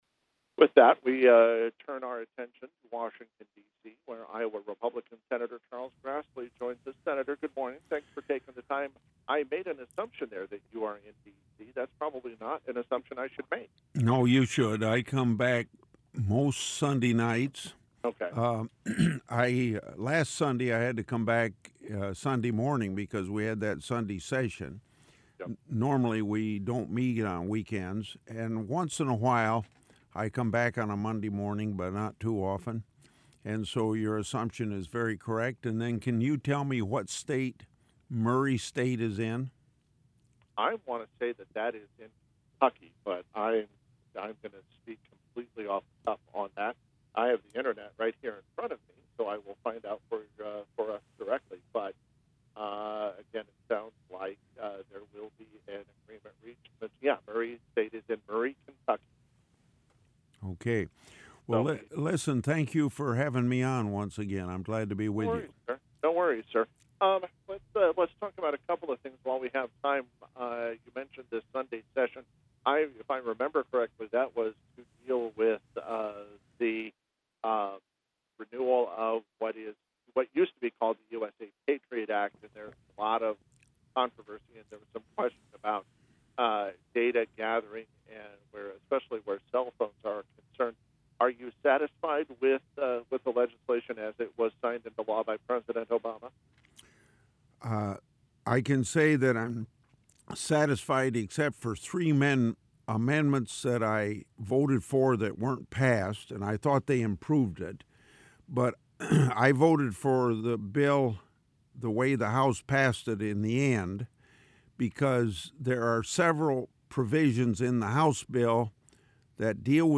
Grassley Live on KASI